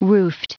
Prononciation du mot roofed en anglais (fichier audio)
Prononciation du mot : roofed